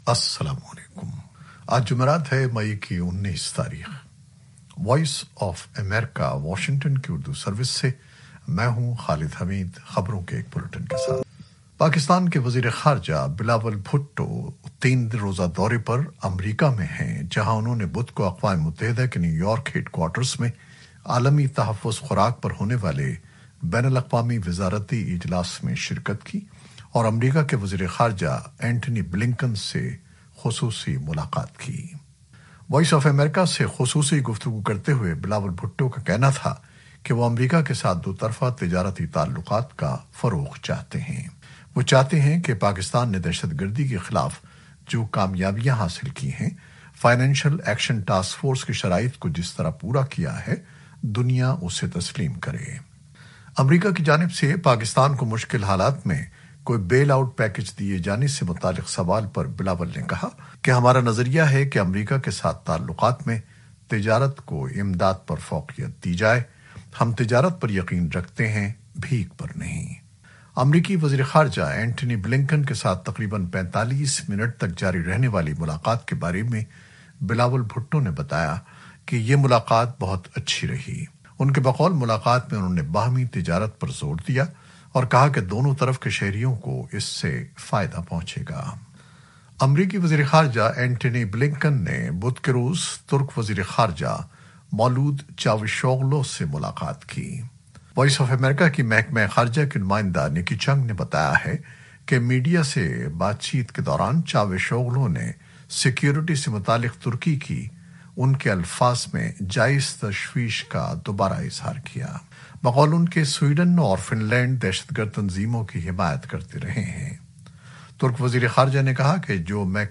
نیوز بلیٹن 2021-19-05